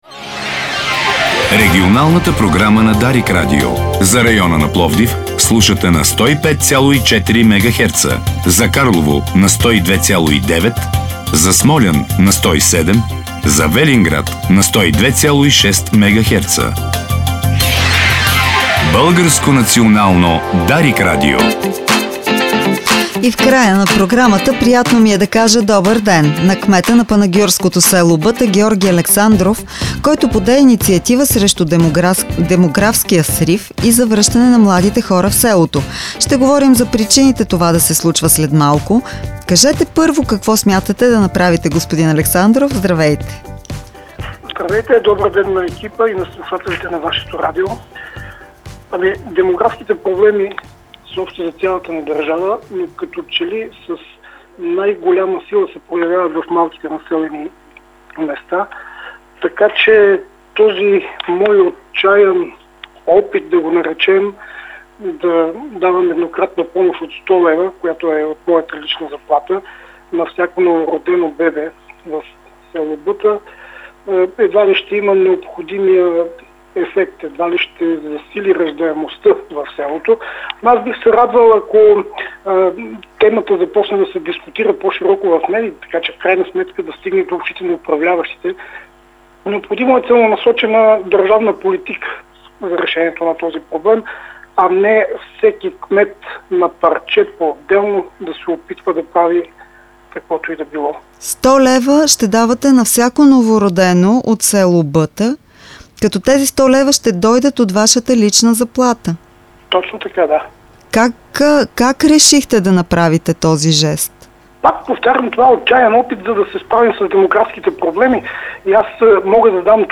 Пред Дарик той нарече жеста си отчаян опит да реагира на демографската криза, да мотивира младите да останат и да заостри вниманието върху проблемите на малките населени места.